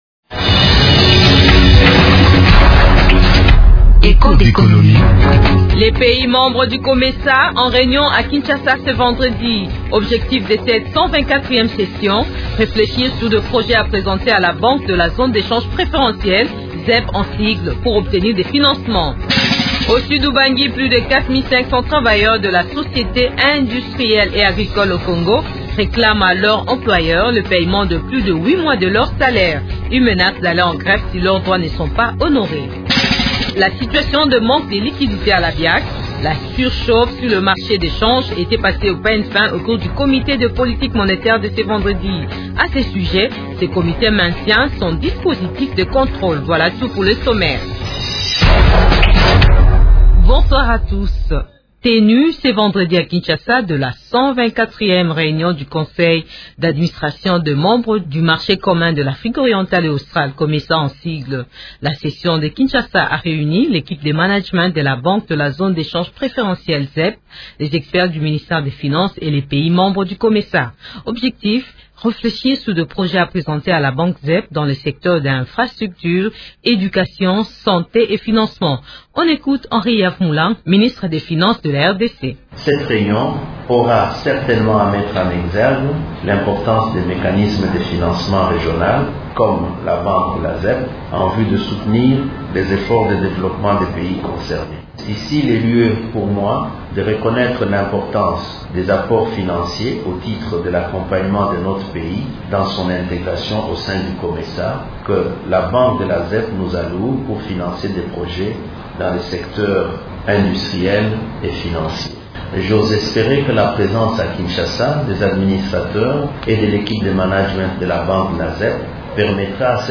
Cette information est traitée dans l’émission « Echos d’Economie » de cette fin de semaine.